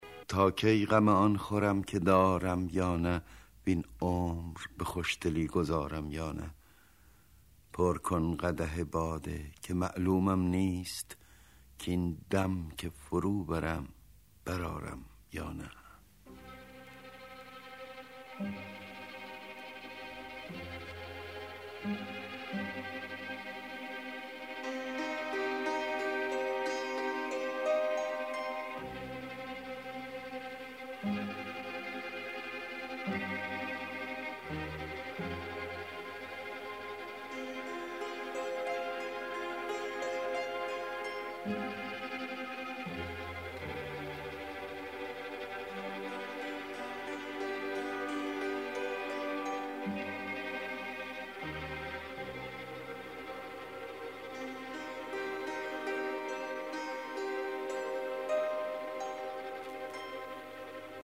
دانلود دکلمه تا کی غم آن خورم با صدای احمد شاملو
گوینده :   [احمد شاملو]